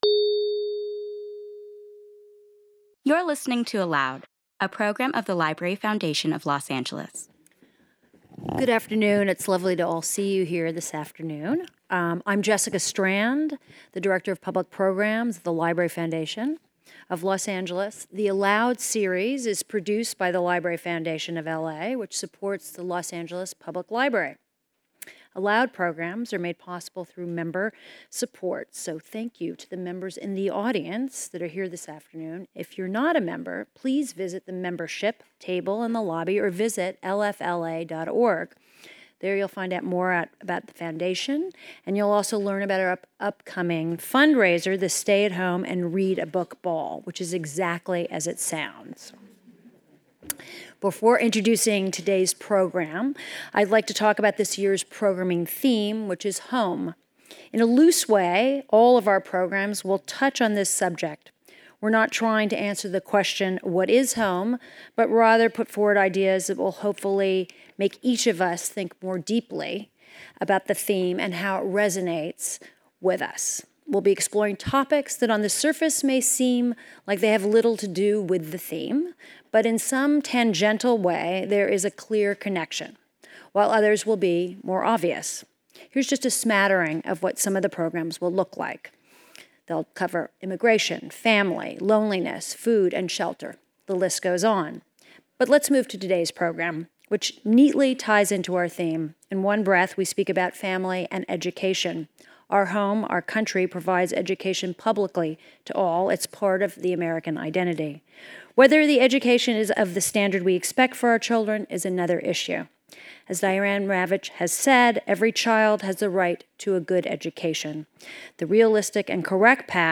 On the heels of the one-year anniversary of LAUSD’s momentous teachers’ strike, we welcome Ravitch to the stage to address some of the most important education issues of our time.